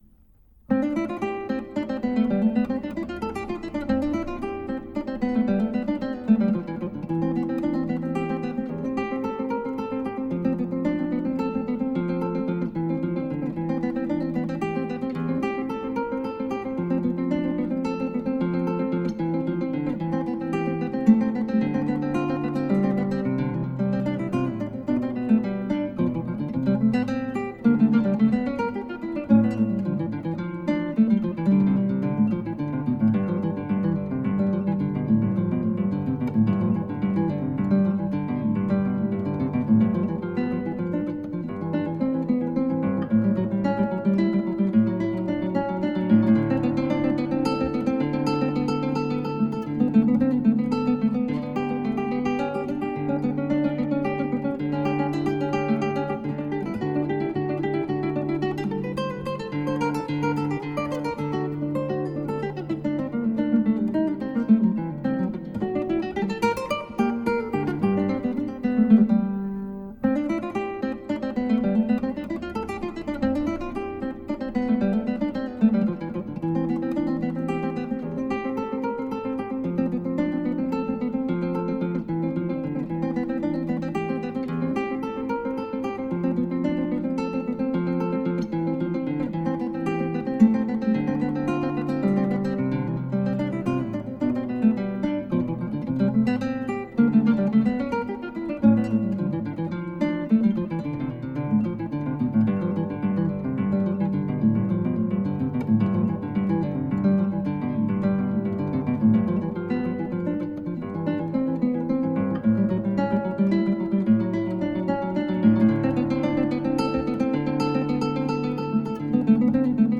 クラシックギター　ストリーミング　コンサート
シェリングくらいのスピードです。
この曲、休む場所が無いんだよ。